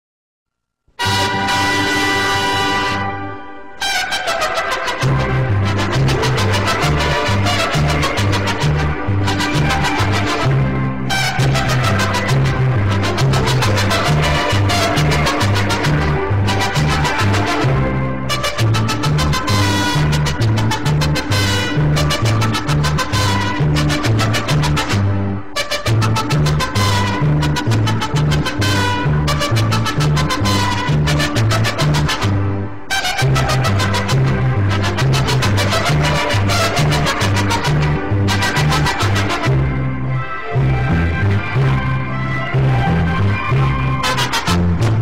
Loud Mexican Music